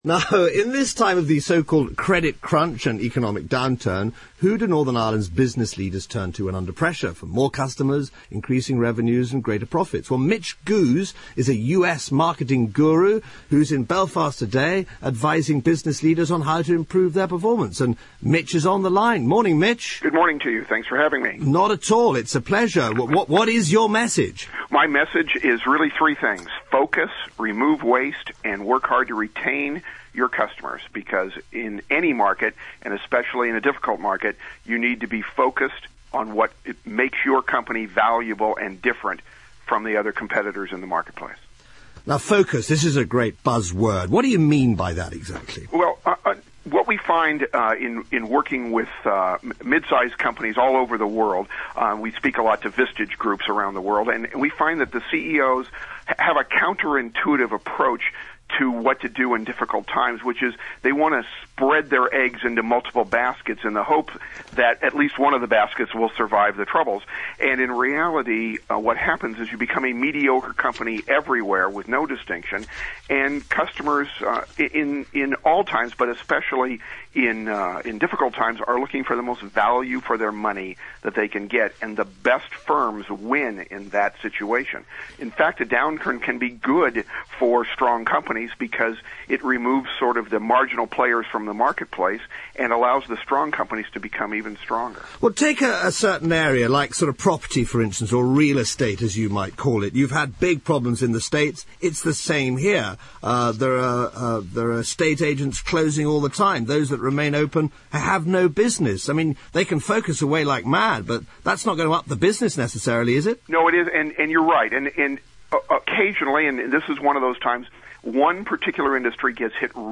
BBC Radio Ulster about dealing with economic downturns (a 5 minute interview